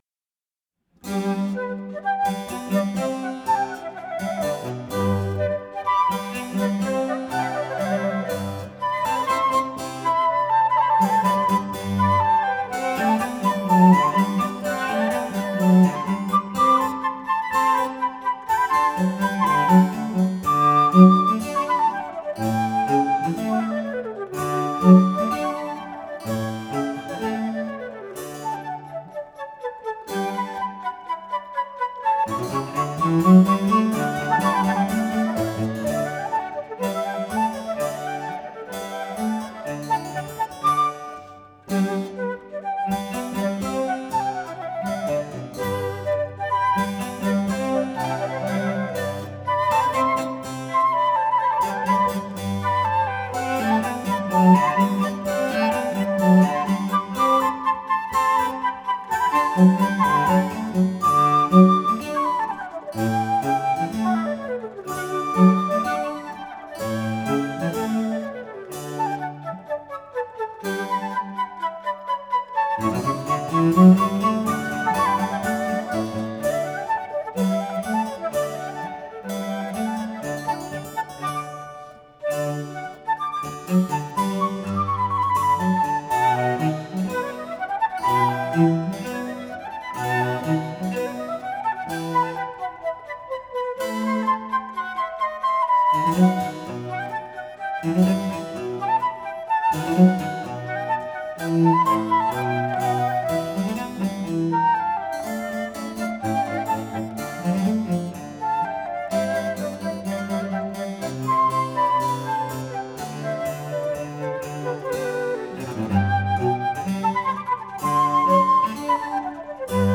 Incamto ensemble | Live recording | InCamTo
Trio in Sol maggiore, per due flauti e continuo
Chiesa di Santa Chiara, Torino